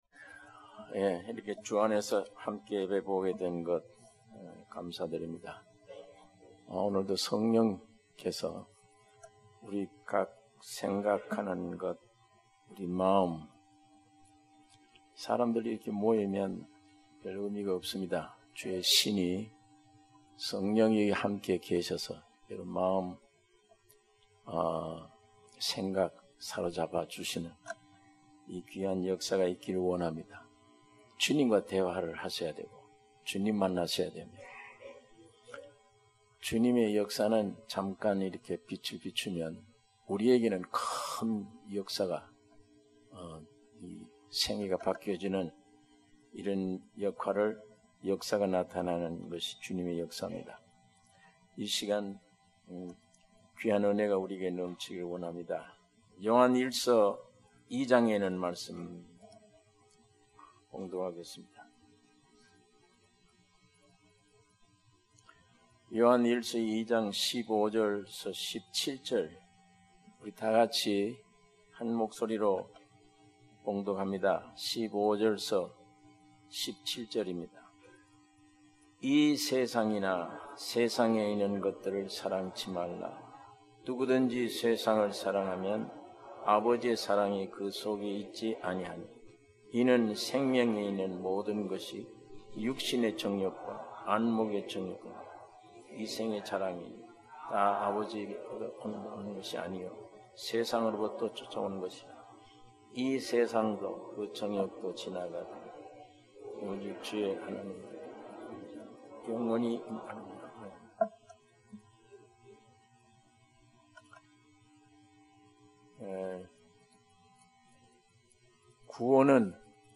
주일예배 - 요한일서 2장 15절 - 17절